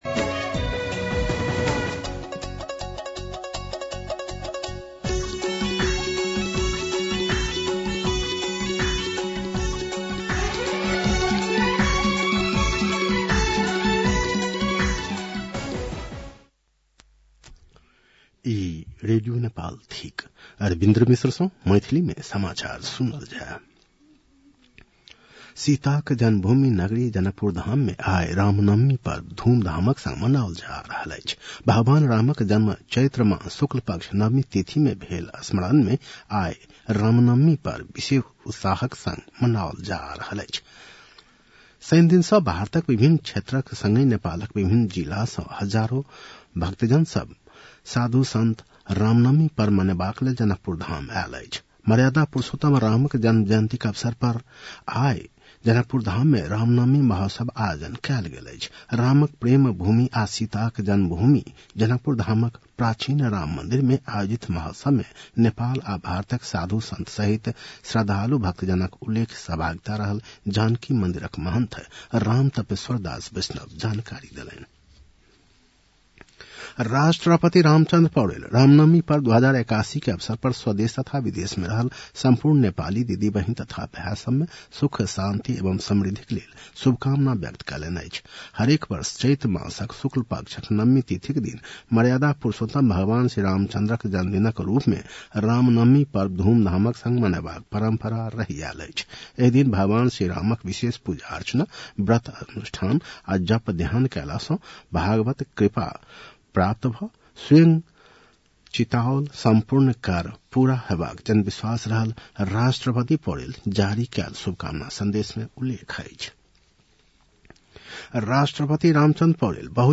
मैथिली भाषामा समाचार : २४ चैत , २०८१
Maithali-News.mp3